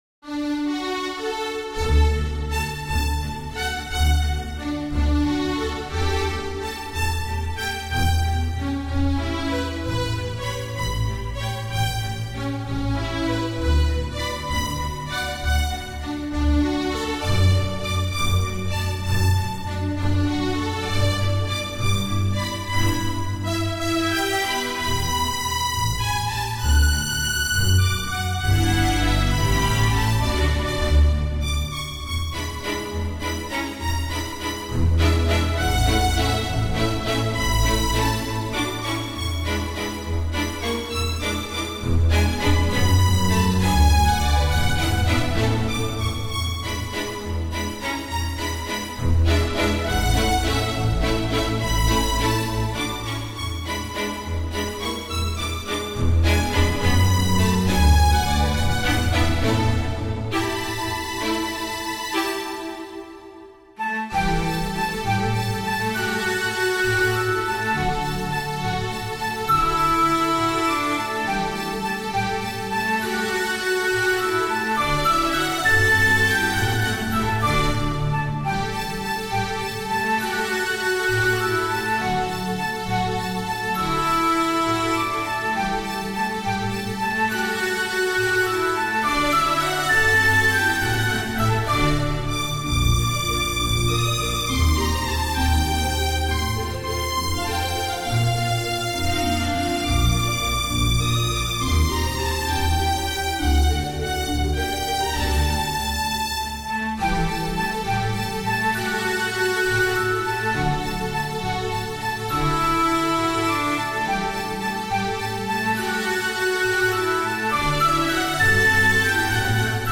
Mid/Uptempo Orchestra
Classical Wedding waltz